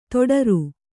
♪ toḍaru